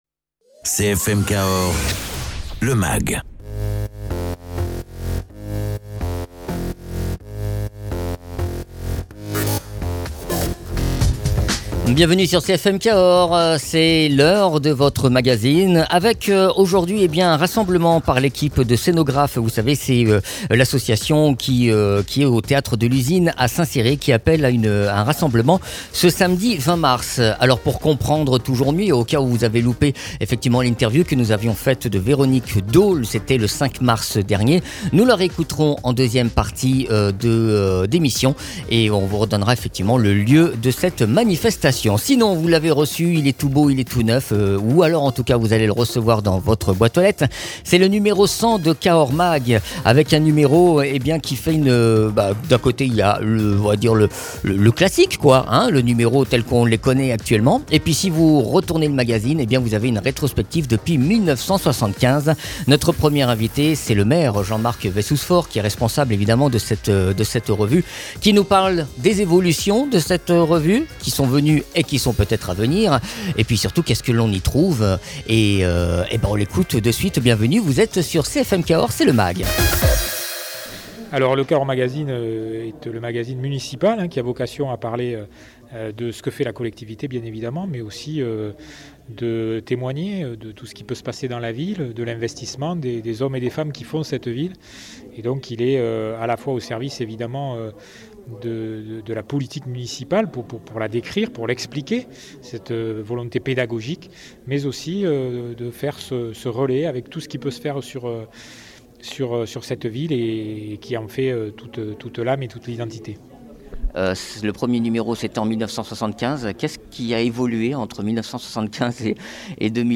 Invité(s) : Jean Marc Vayssouze-Faure, Maire de Cahors et directeur de la publication